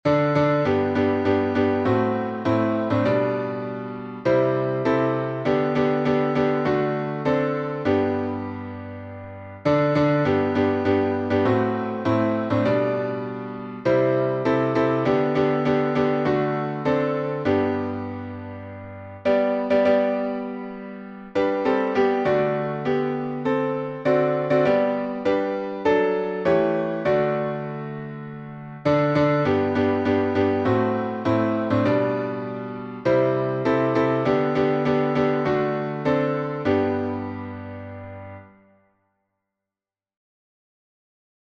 Key signature: G major (1 sharp) Time signature: 4/4 Meter: 11.8.11.9. with Refrain